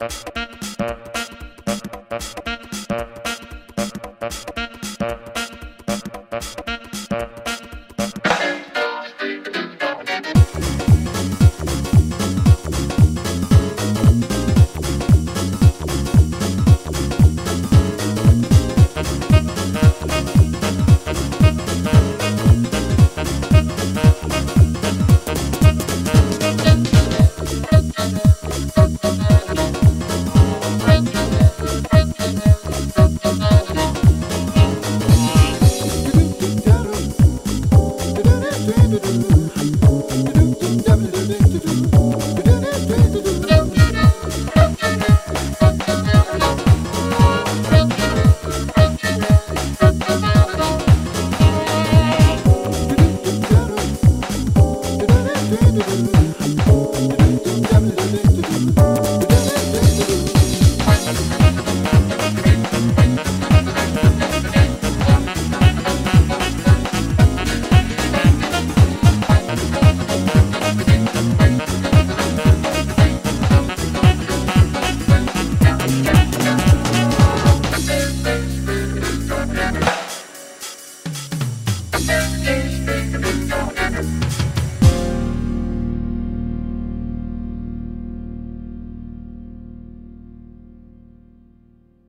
BPM114
Audio QualityLine Out